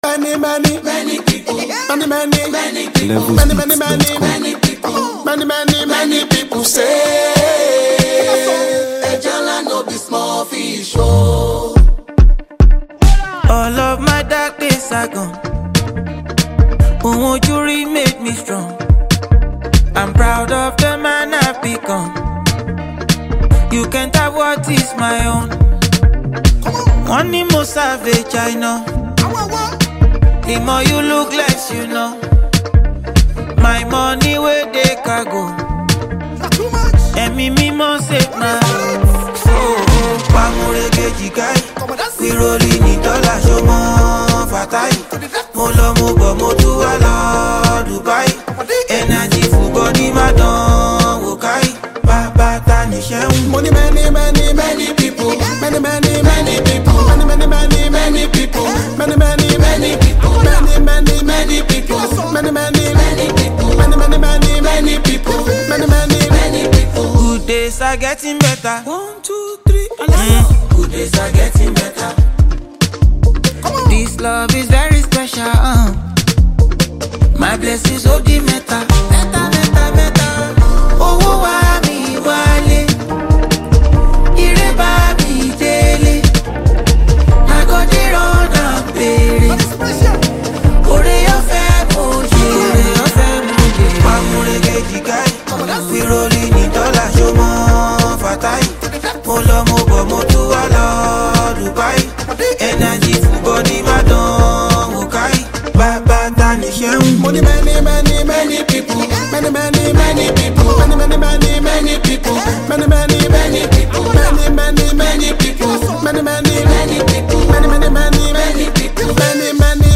Nigeria Music
Afrobeats
On this soulful and reflective record
Backed by smooth instrumentation and rich melodies